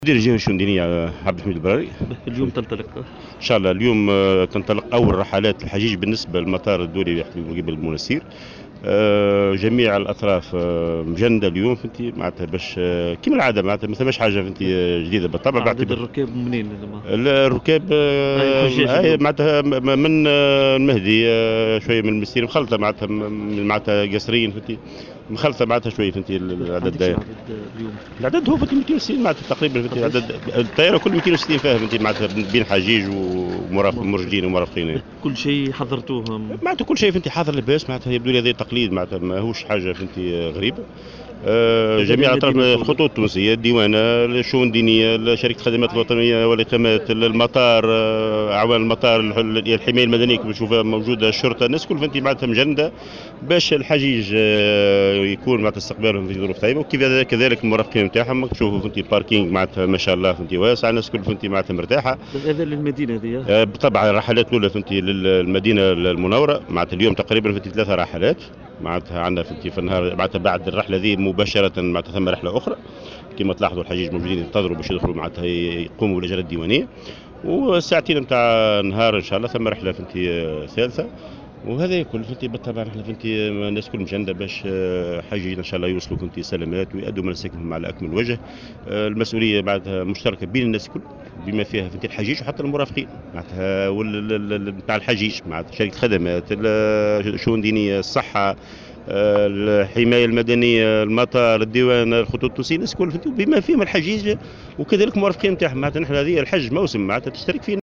وأضاف في تصريح لمراسل "الجوهرة أف أم" ان الرحلات انطلقت في احسن الظروف وبنسق عادي مشيرا إلى انه تمت برمجة 3 رحلات اليوم إلى البقاع المقدسة.